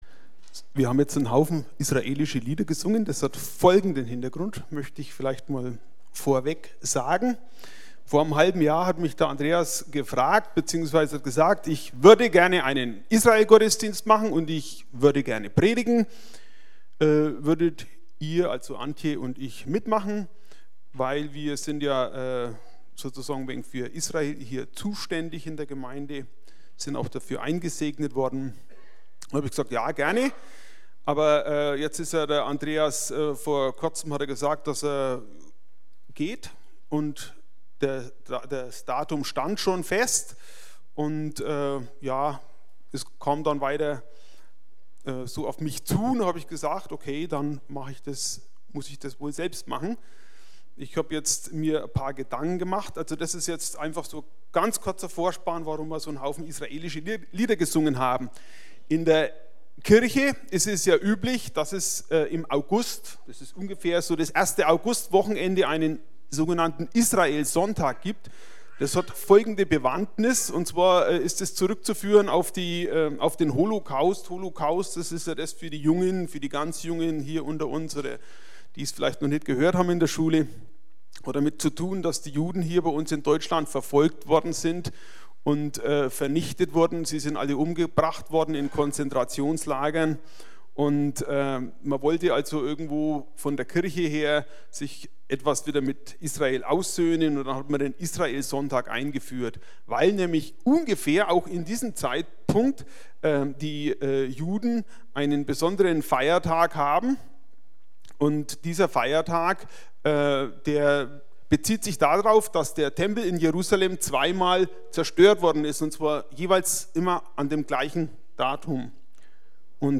Kirche am Ostbahnhof Navigation Infos Über uns…
Predigten